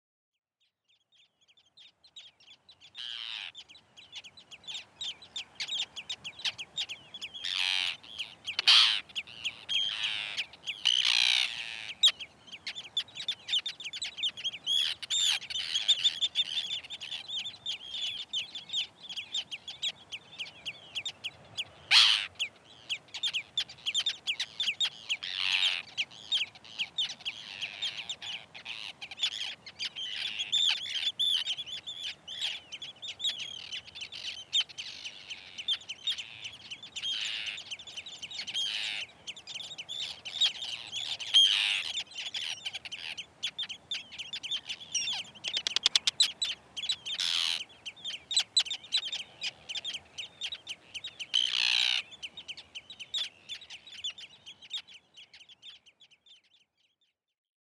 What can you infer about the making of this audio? Reviews This recording takes you on a round trip of Iceland. The recordings do not feature human-derived sounds. 1. 4_westfjords_excerpt.mp3